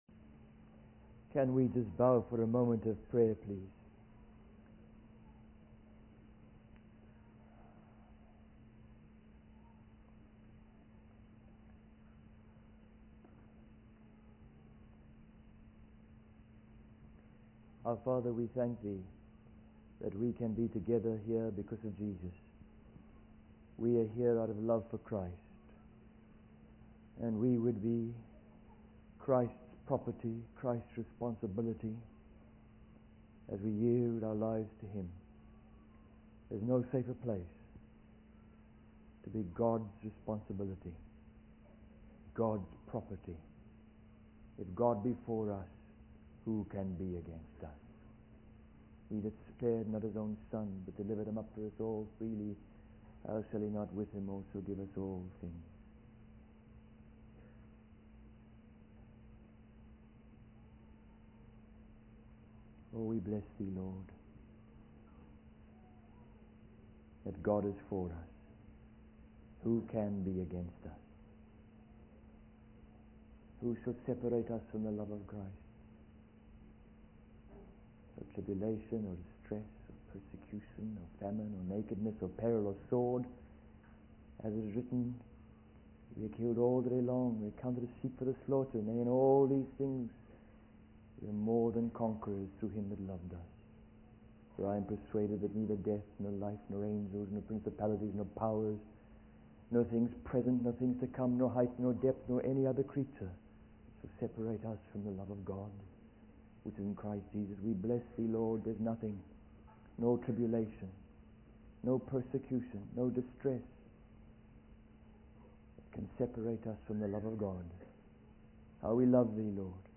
In this sermon, the preacher discusses the current state of families and the influence of children over their parents. He mentions the controversy of television in Christian homes, with many families choosing to remove it due to its negative impact on children.